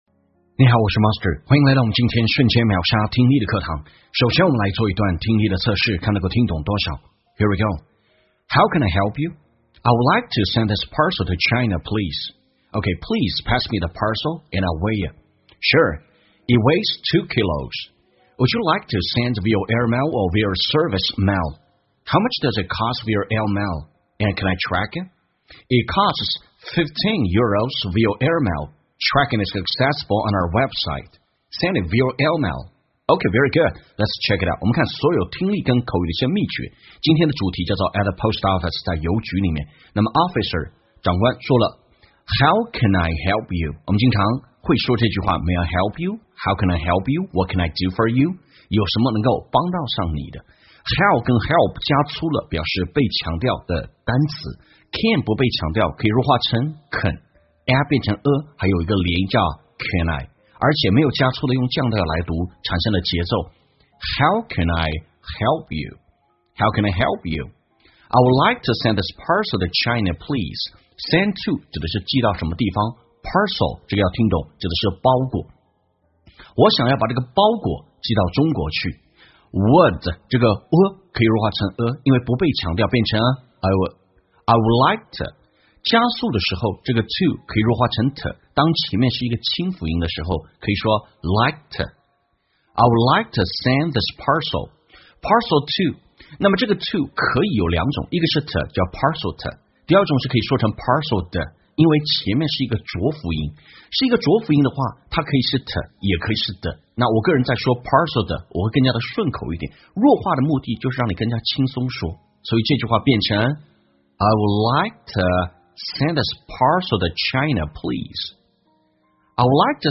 在线英语听力室瞬间秒杀听力 第600期:在邮局的听力文件下载,栏目通过对几个小短句的断句停顿、语音语调连读分析，帮你掌握地道英语的发音特点，让你的朗读更流畅自然。